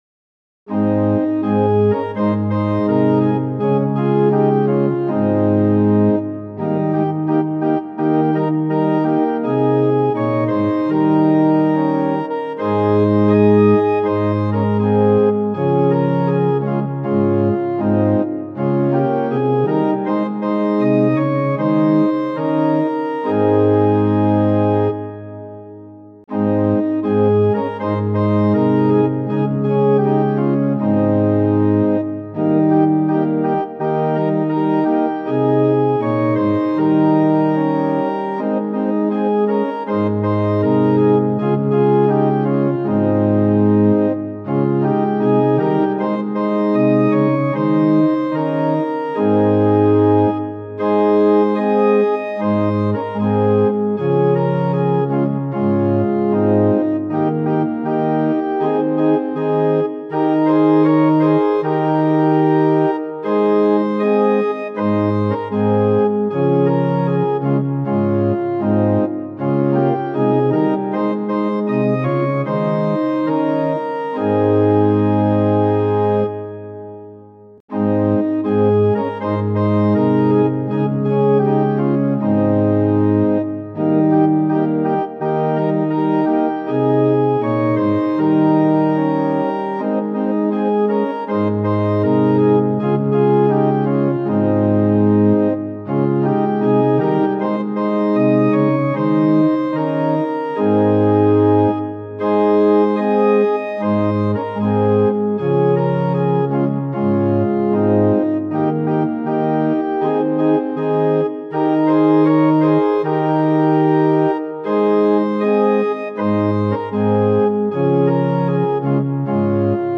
Tonality = As Pitch = 440 Temperament = Equal